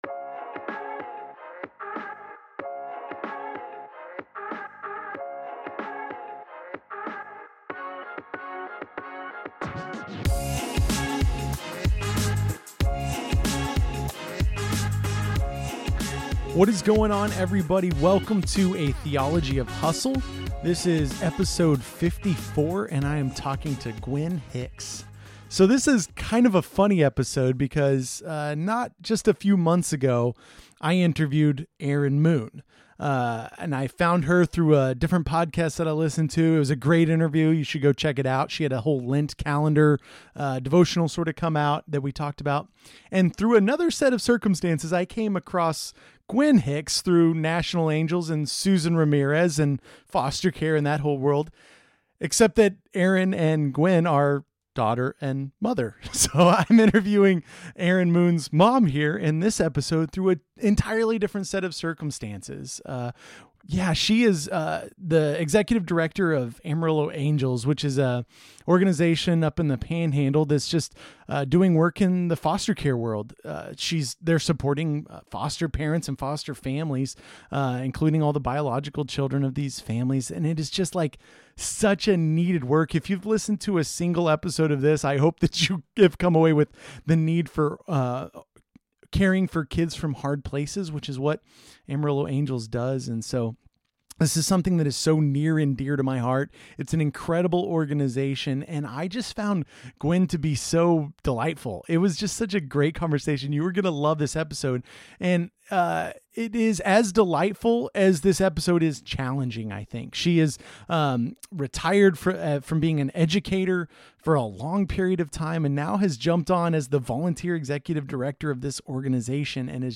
Their mission is to support families doing the work of foster care in the Texas panhandle. This interview is...